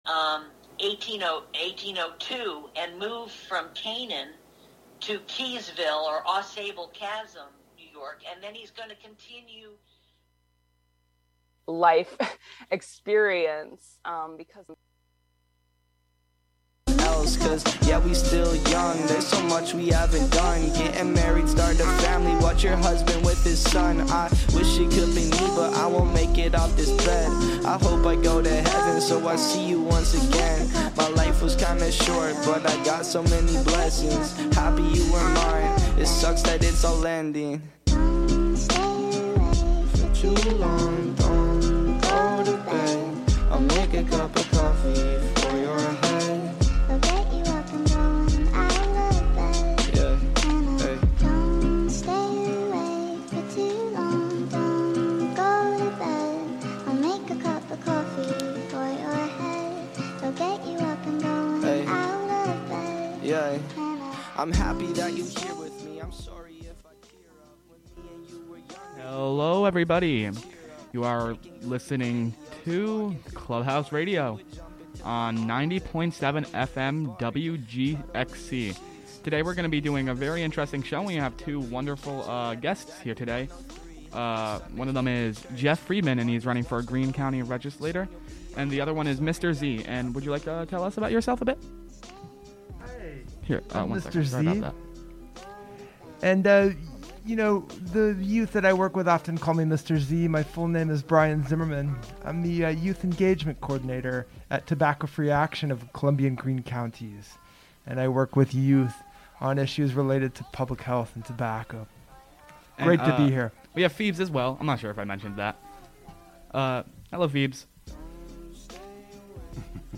Show includes local WGXC news at beginning, and midway through.
live on Fridays as part of All Together Now!